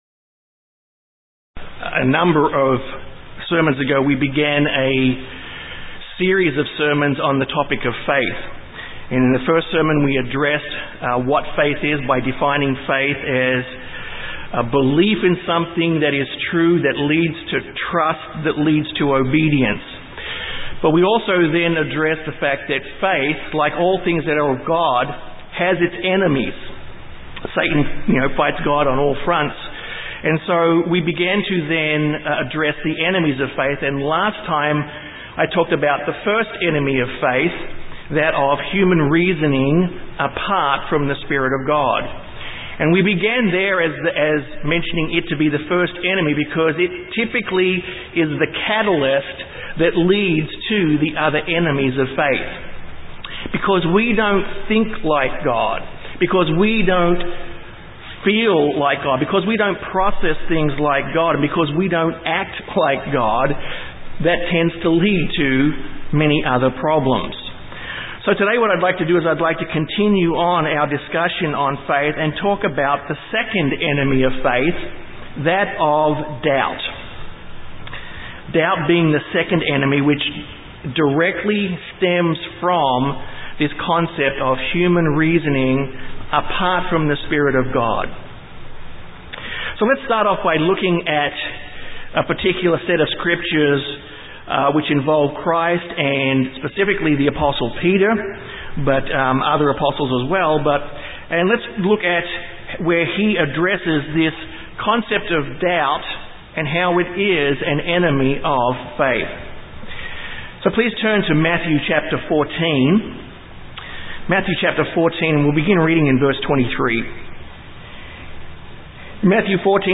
This Sermon discusses 2nd Enemy of Faith - Doubt. To have doubt is to be double minded.
Given in San Antonio, TX